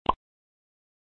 click.mp3